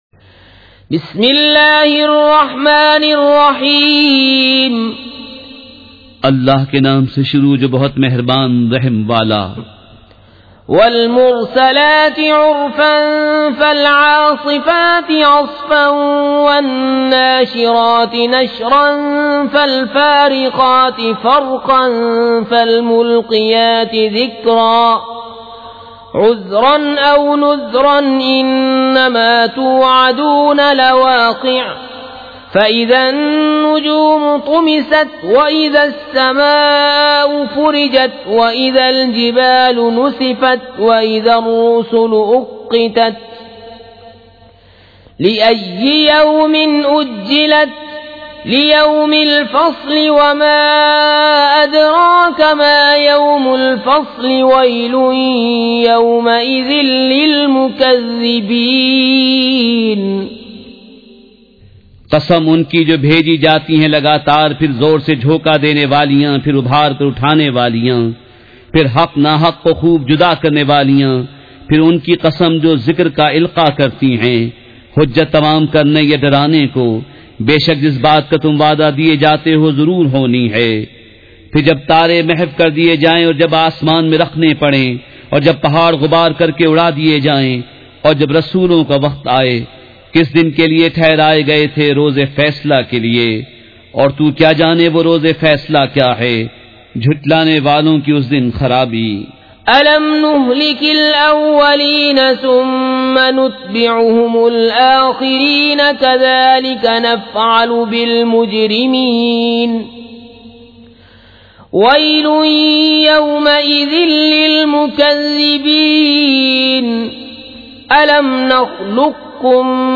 سورۃ المرسلات مع ترجمہ کنزالایمان ZiaeTaiba Audio میڈیا کی معلومات نام سورۃ المرسلات مع ترجمہ کنزالایمان موضوع تلاوت آواز دیگر زبان عربی کل نتائج 1855 قسم آڈیو ڈاؤن لوڈ MP 3 ڈاؤن لوڈ MP 4 متعلقہ تجویزوآراء